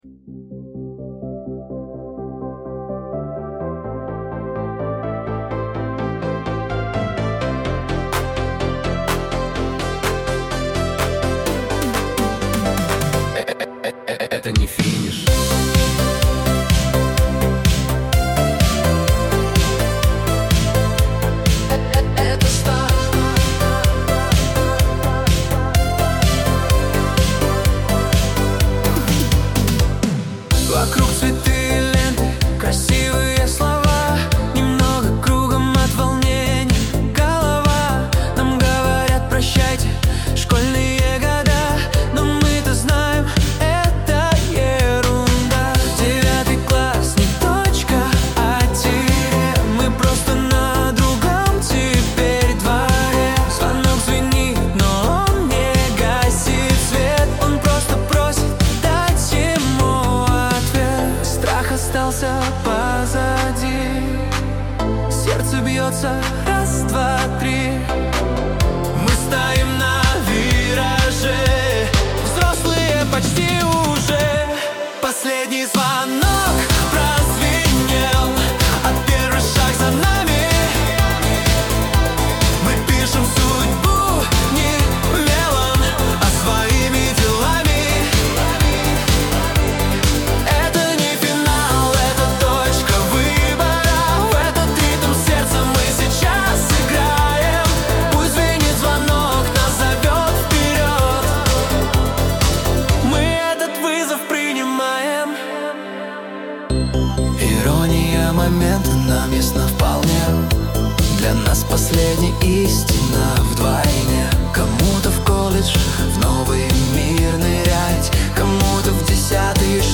Синти-поп / Флешмоб
Синти-поп 80-х (125 BPM).
🎹 Вайб: Дискотека 80-х